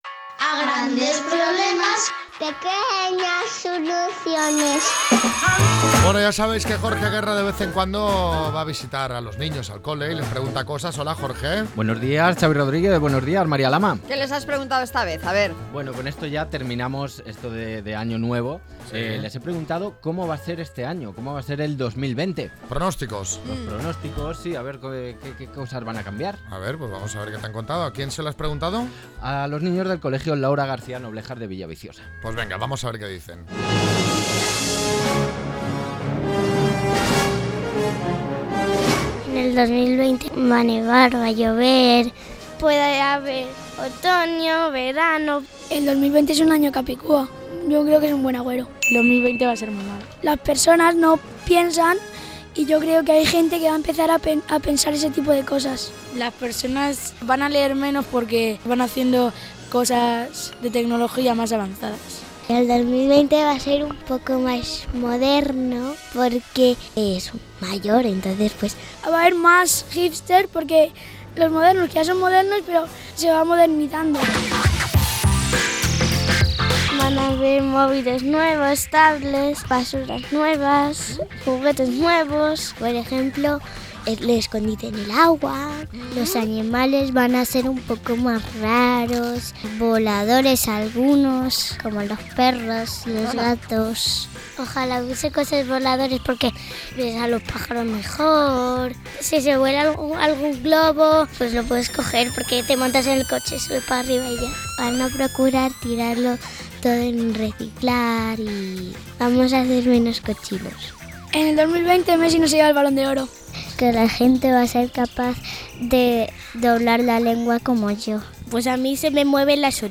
Los niños nos hacen los pronósticos para el nuevo año.